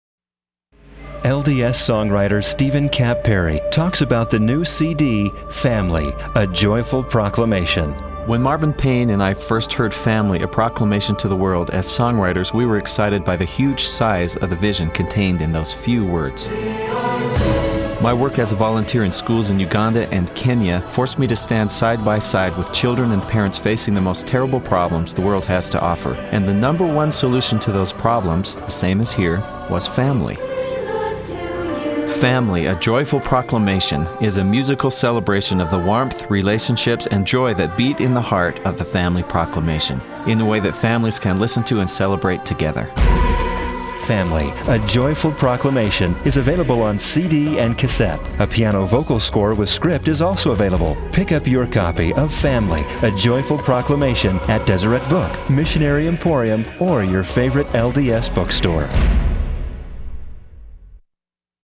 a musical presentation for chorus, soloists and readers